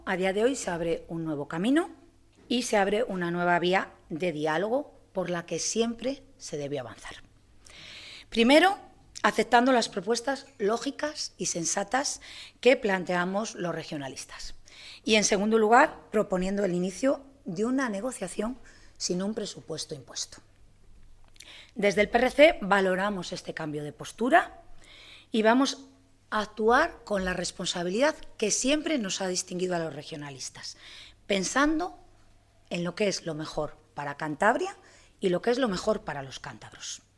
Fernández Viaña durante su comparecencia ante los medios de comunicación.